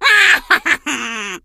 diva_kill_vo_05.ogg